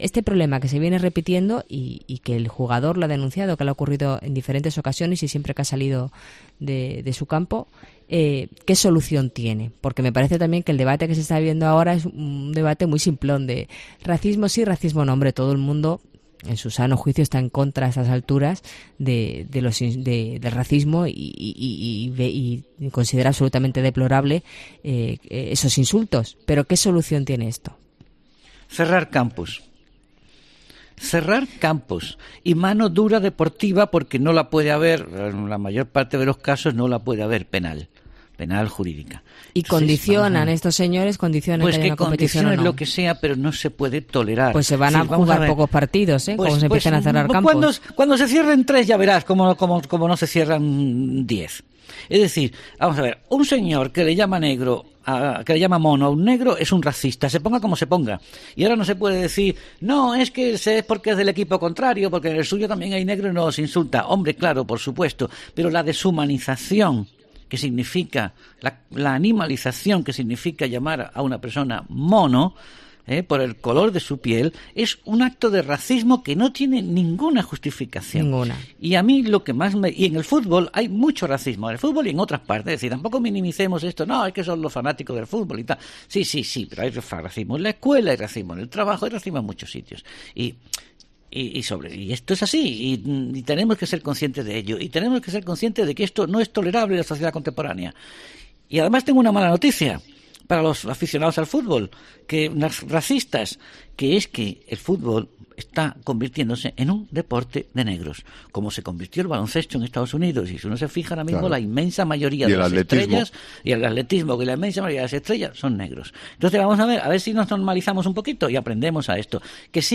El colaborador de La Linterna estalla durante la tertulia del programa de COPE sobre el jugador del Real Madrid